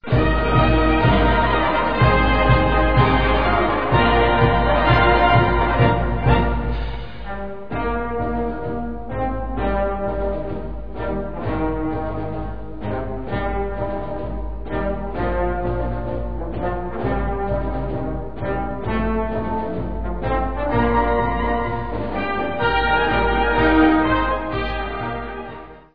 Gattung: Strassenmarsch
Besetzung: Blasorchester
Im 2.Teil ist das Thema des "Zapfenstreichs" eingefügt.